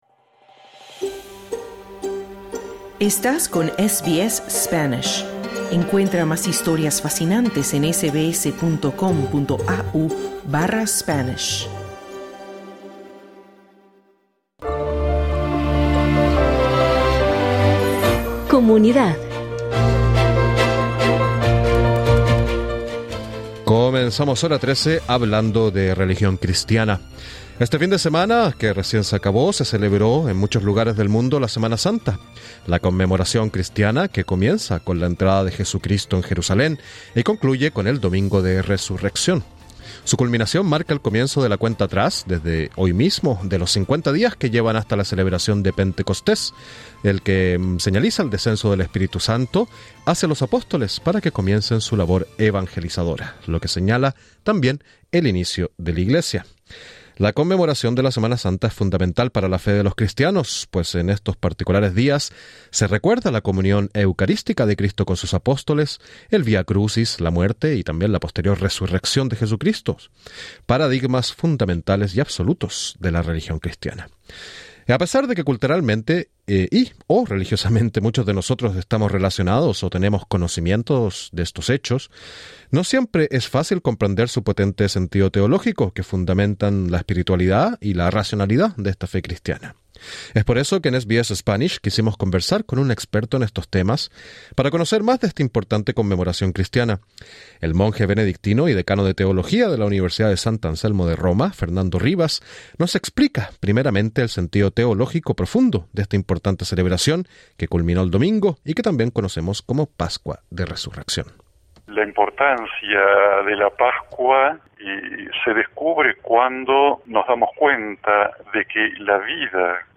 Sin embargo, el sentido teológico profundo de algunos de estos hechos y su importancia para el cristianismo no son tan conocidos. Conversamos con un teólogo experto para ampliar el significado de estas tradiciones religiosas.